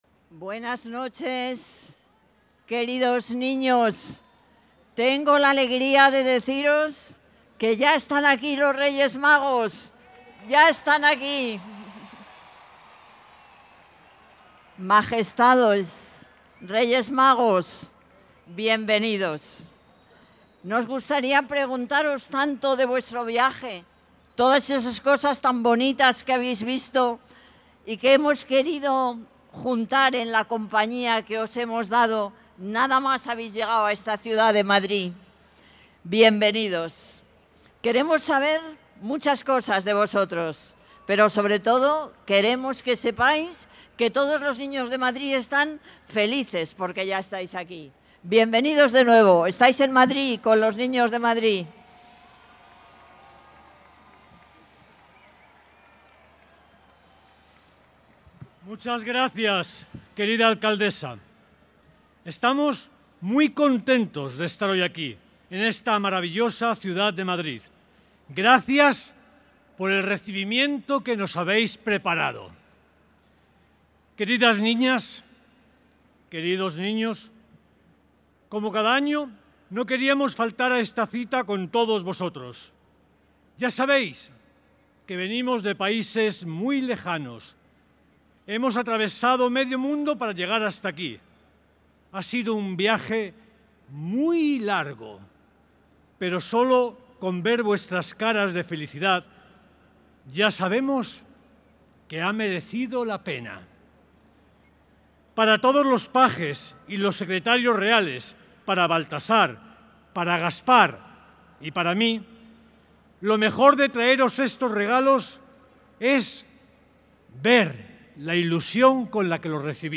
La alcaldesa da la bienvenida a Melchor, Gaspar y Baltasar al final de la Cabalgata, que ha contado con 30 espectáculos y 2.000 participantes
Nueva ventana:Manuela Carmena, alcaldesa de Madrid y discurso de Melchor